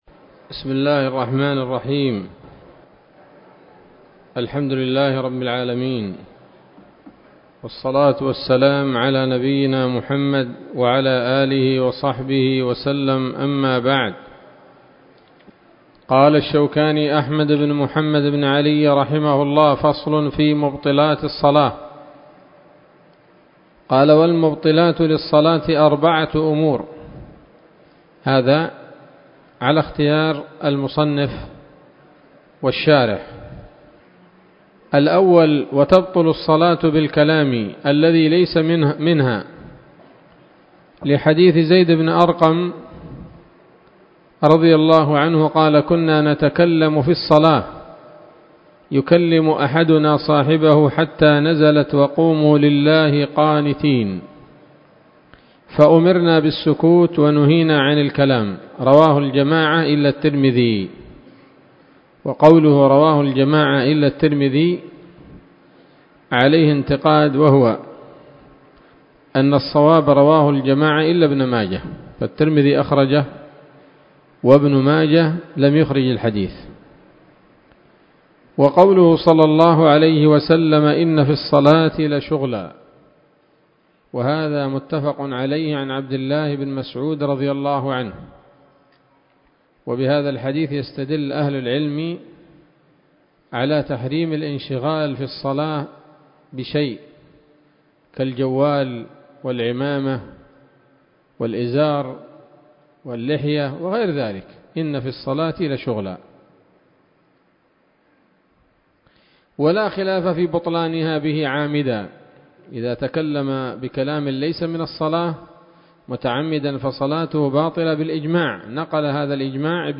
الدرس الثامن عشر من كتاب الصلاة من السموط الذهبية الحاوية للدرر البهية